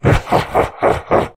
spawners_mobs_balrog_neutral.1.ogg